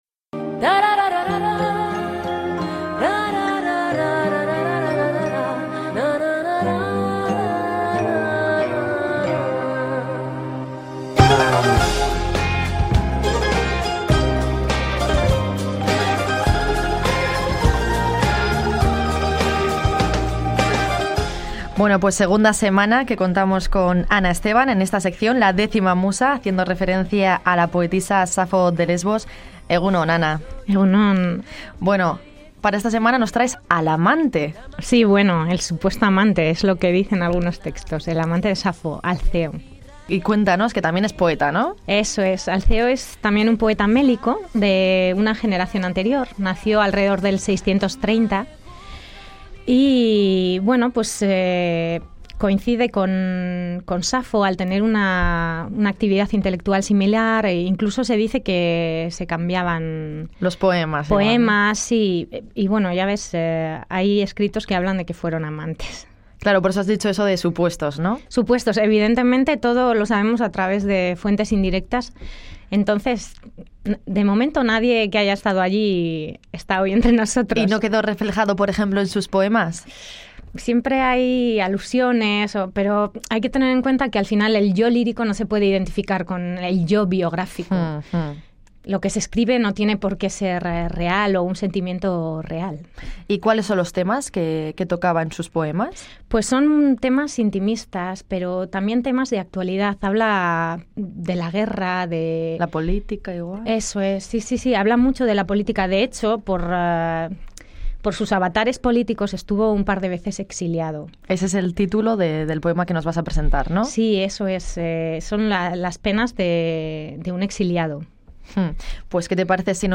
En “La décima musa” leemos un fragmento lírico de Alceo de Mitilene, supuesto amante de Safo, en el que habla de los pesares de un desterrado.